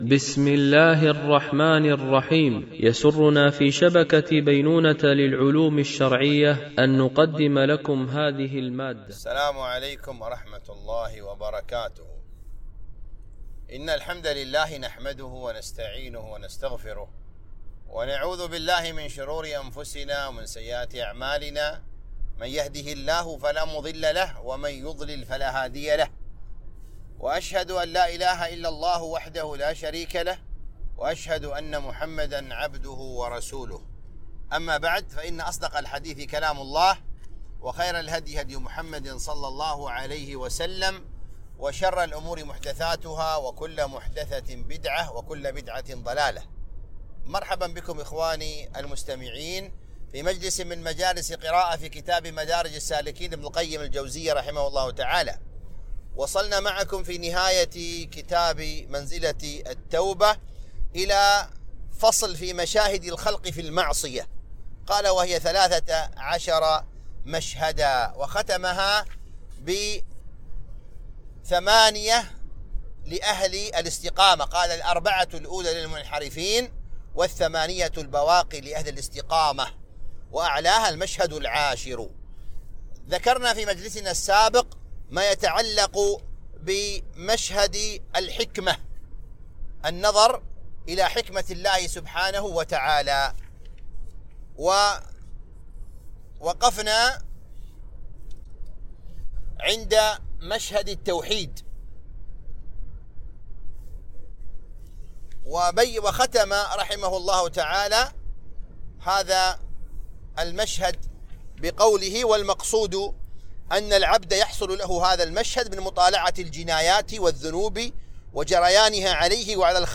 قراءة من كتاب مدارج السالكين - الدرس 42